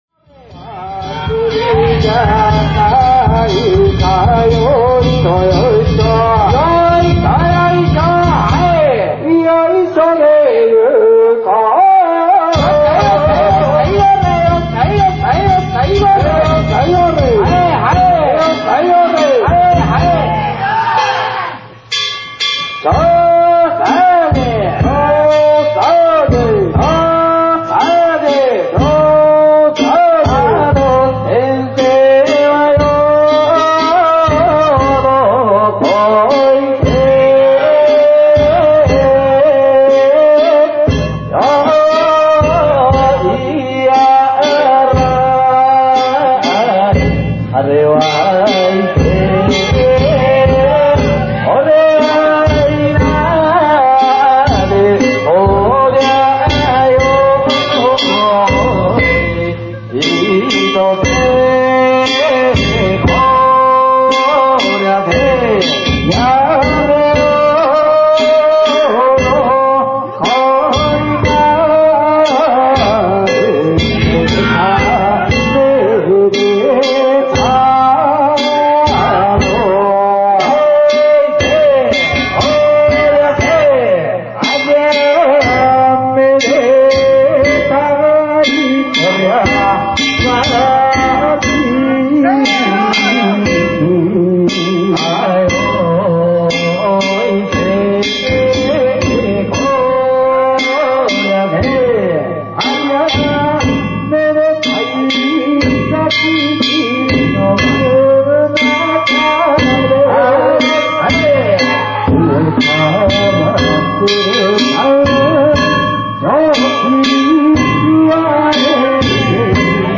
曳き唄唄いながらの宮入曳行です。
坂の途中からは伊勢音頭に変わりました。